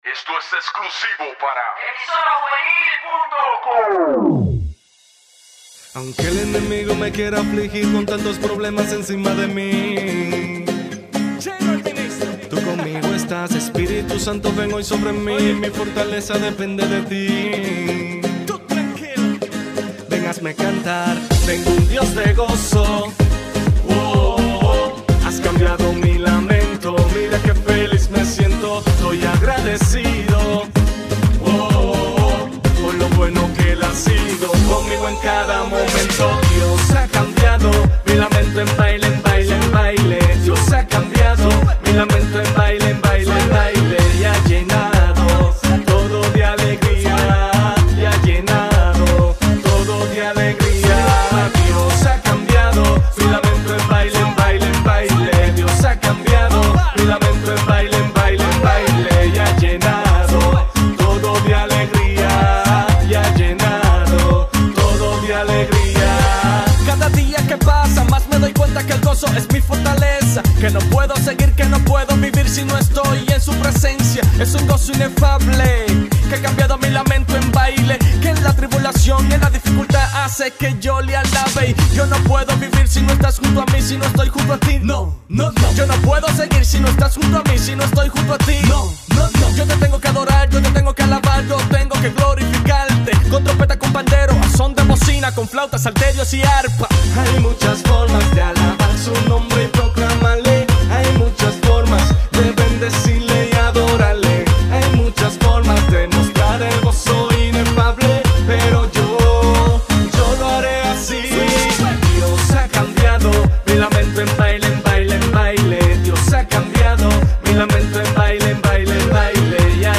Música Cristiana